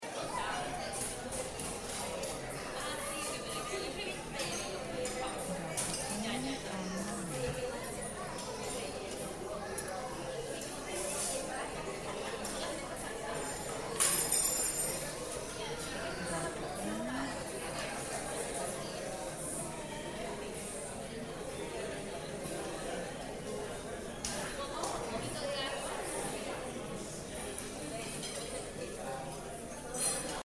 Ambiente Estéreo Interior Local Desayunos Acción
INTERIOR LOCAL DESAYUNOS, GENTE INTERACTUANDO, PLATOS Y VAJILLA, NIÑOS, FAMILIAS (2min22seg).
Archivo de audio ESTÉREO, 96Khz – 24 Bits, WAV.
INTERIOR-LOCAL-DESAYUNOS_GENTE-INTERACTUANDO-96KHZ.mp3